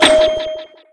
bumperding1.wav